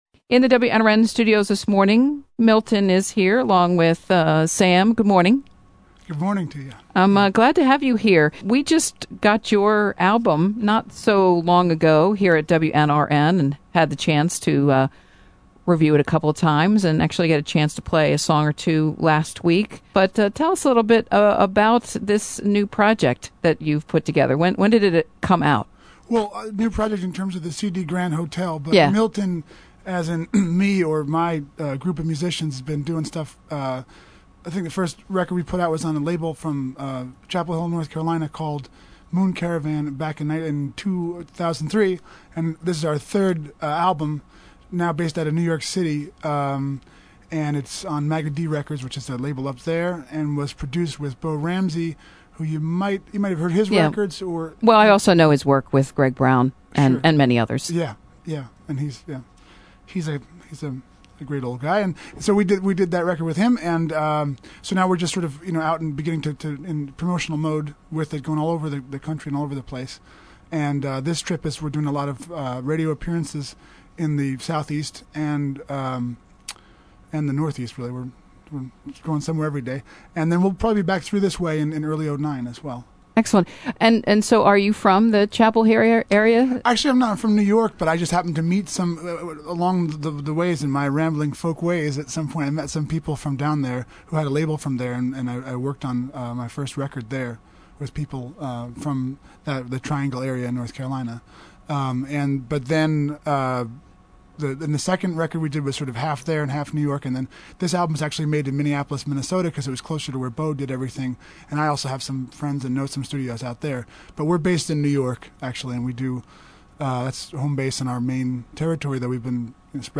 interview
played a few songs